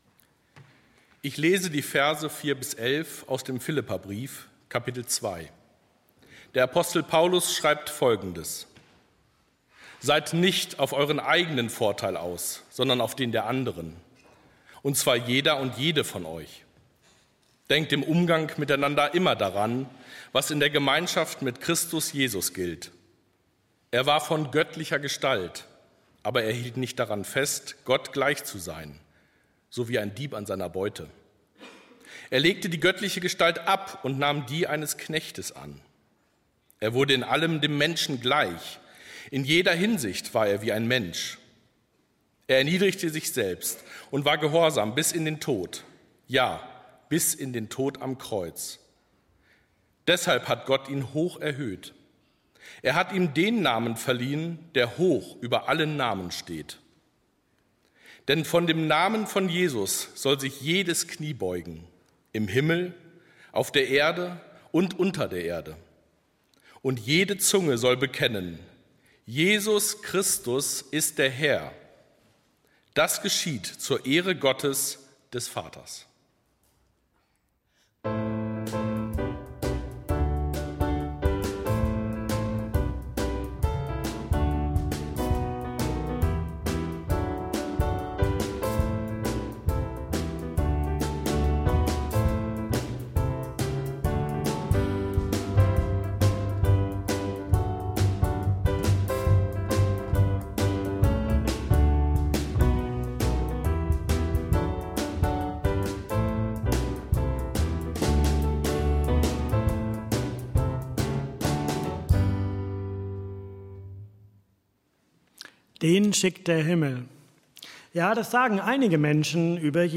Radiogottesdienst: Dich schickt der Himmel
radiogodi_ndr_predigt_lang.mp3